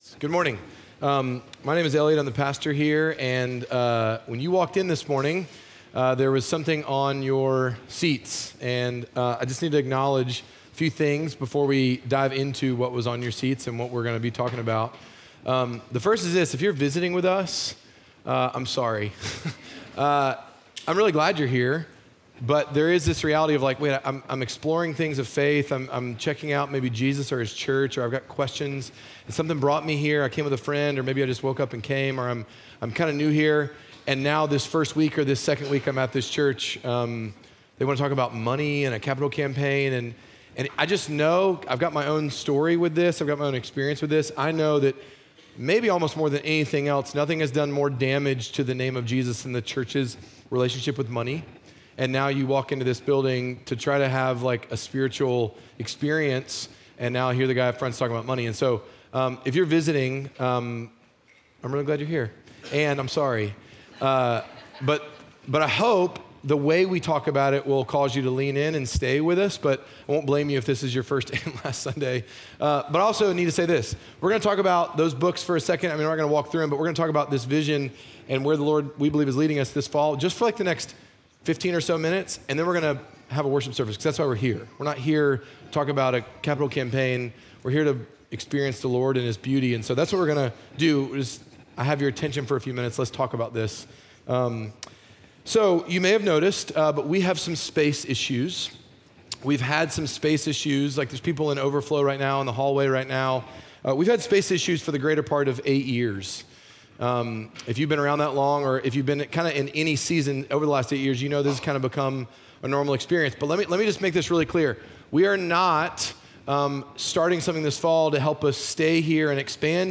Midtown Fellowship 12 South Sermons Vision: Living a Legacy Sep 08 2024 | 00:19:37 1x 00:00 / 00:19:37 Subscribe Share Apple Podcasts Spotify Overcast RSS Feed Share Link Embed